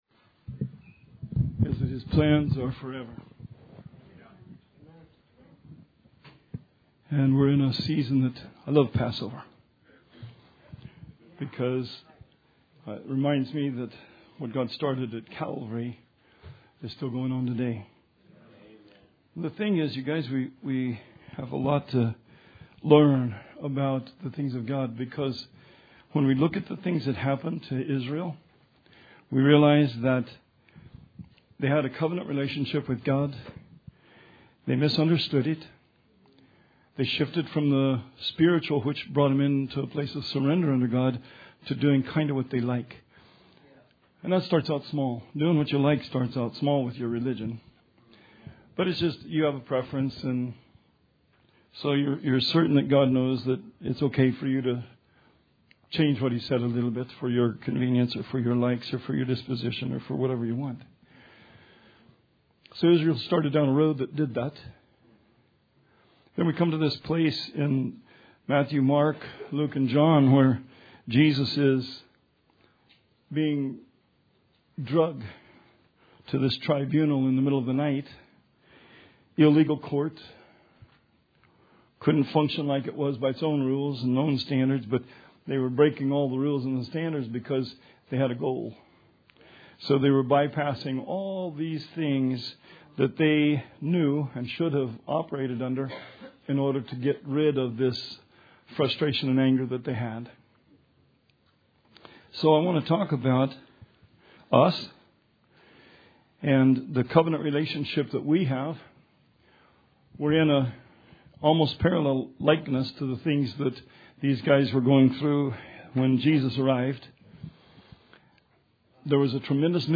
Sermon 4/21/19